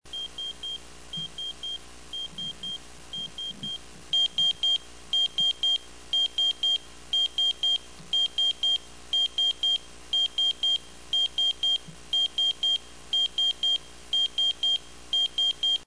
sonnerie